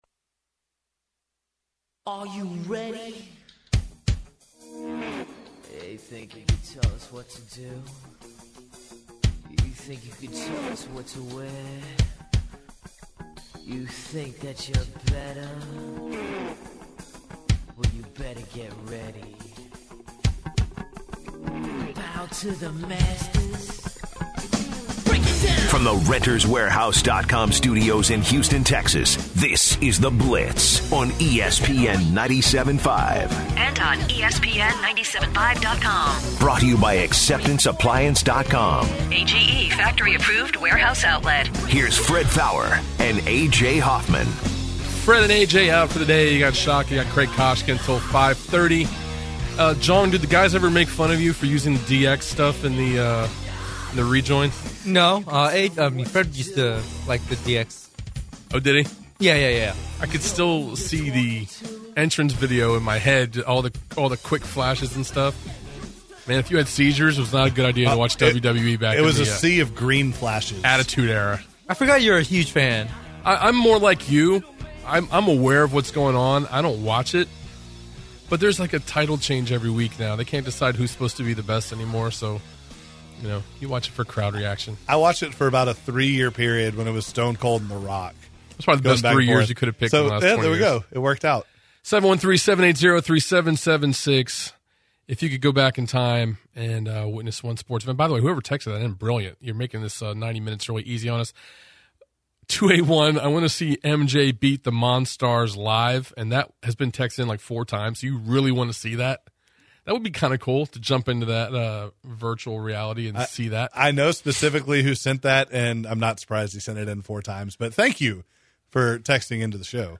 In the short second hour of The Blitz the callers took over to give their opinions on what sporting event they would most like to go to again.